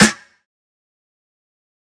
kits/OZ/Snares/Sn (Karaoke).wav at main
Sn (Karaoke).wav